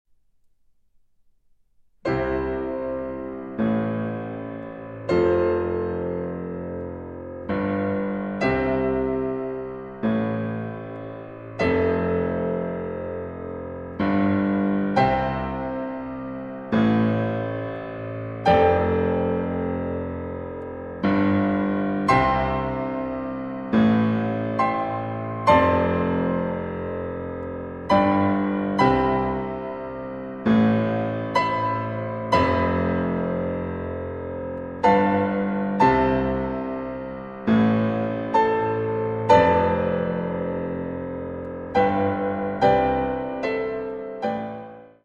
Largamente sostenuto e solenne